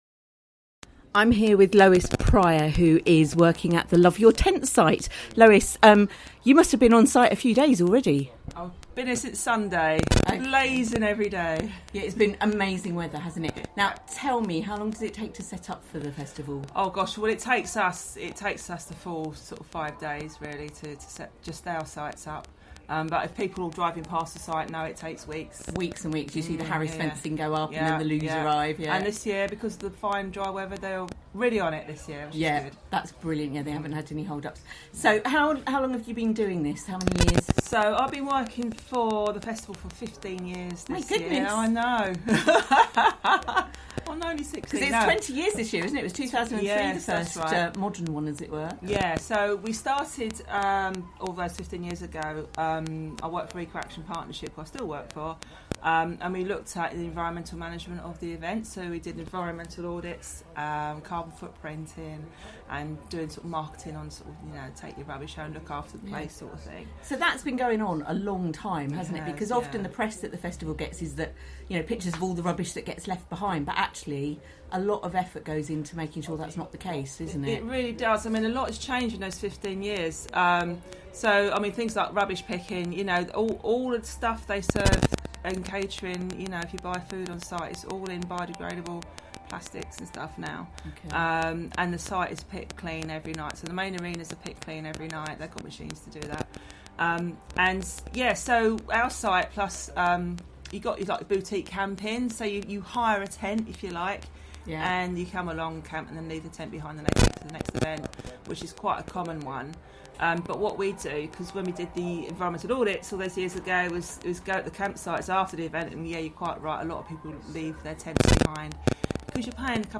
Love Your Tent: Interview